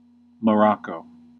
4. ^ /məˈrɒk/
En-us-Morocco.ogg.mp3